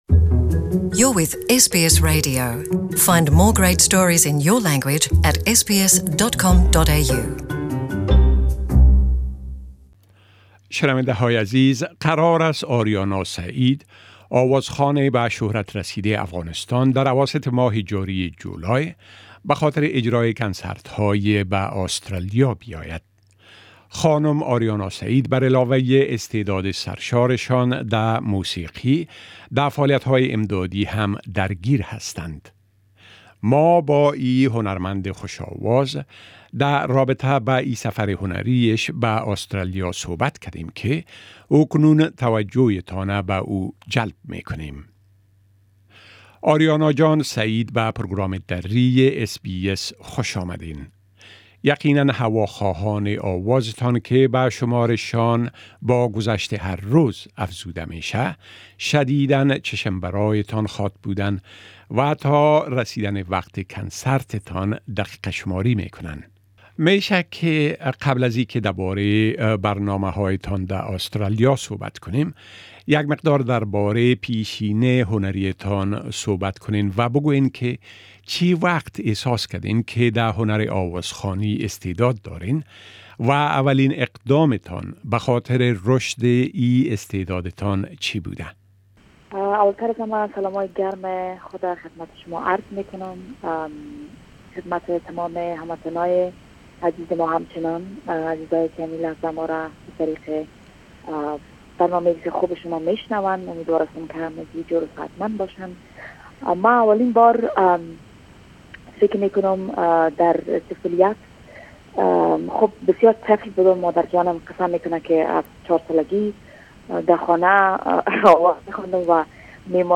Interview with prominent Afghan singer Ariana Sayeed
Afghan renowned singer Aryana Sayeed, in an interview with the SBS Dari program, talks about her forthcoming tour of Australia as well as her artistic background and achievements in Dari language.